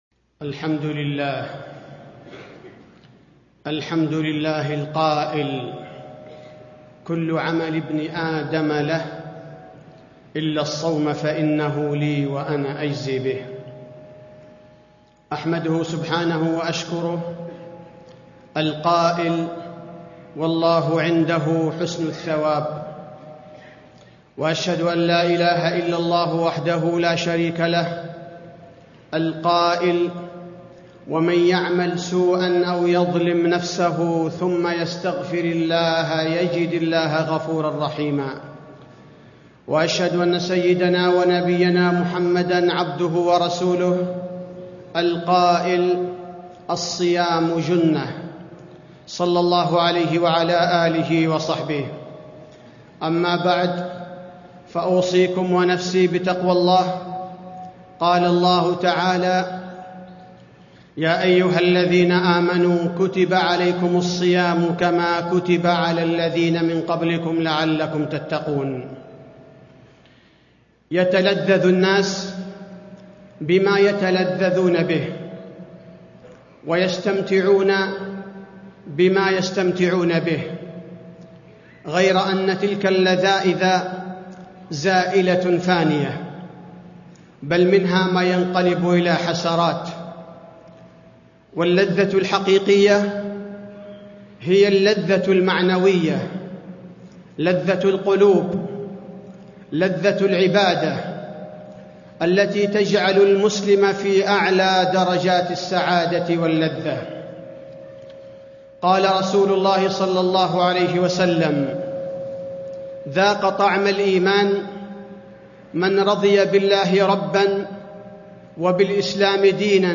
تاريخ النشر ٦ رمضان ١٤٣٥ هـ المكان: المسجد النبوي الشيخ: فضيلة الشيخ عبدالباري الثبيتي فضيلة الشيخ عبدالباري الثبيتي لذة العبادة